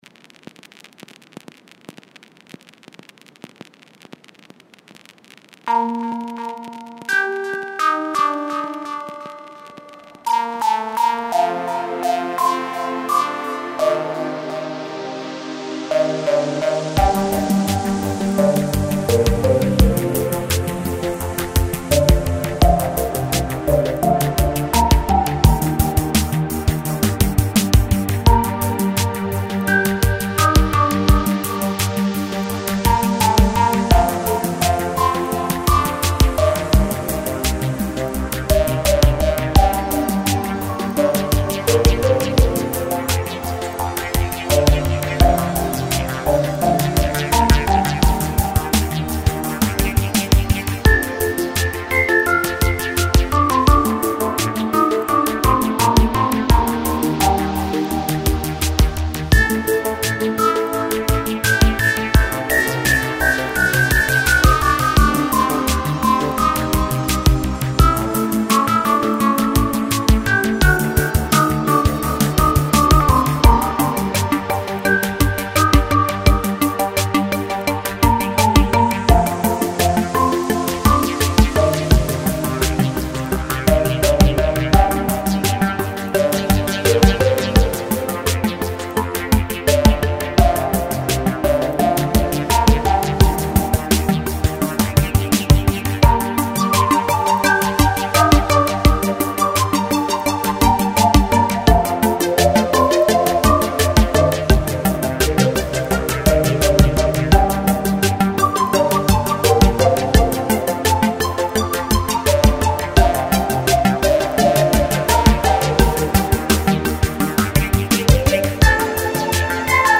Рубрика: Поезія, Авторська пісня
сказати по чесному- я з твоєї Саги тільки дізналася,що гіацинт-це не тільки квітка...соромно було...тому перечитала багато одразу....і задумала ось зробити таке відео smile рада,що тобі сподобалось, класна мелодія, було натхнення
give_rose така композиція..... 39 39 39 красиво дуже..незвичайно...космічно....сподобалось...мрійливо..